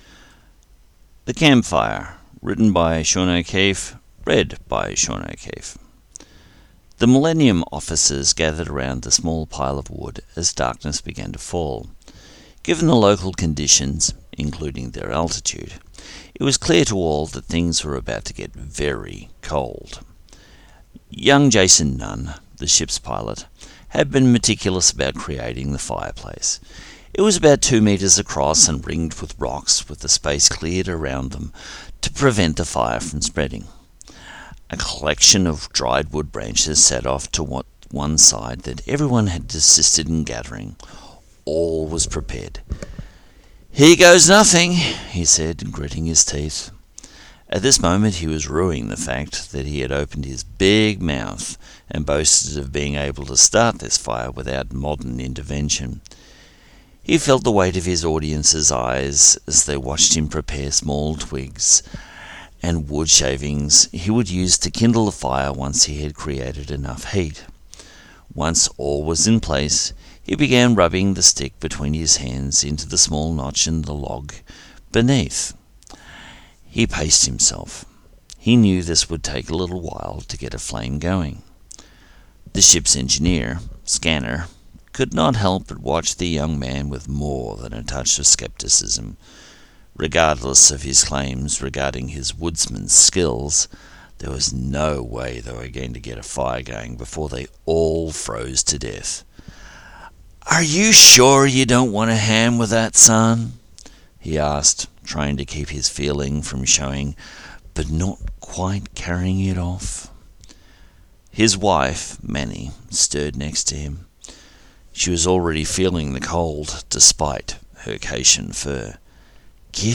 Categories: Short Stories, Audio Books/Drama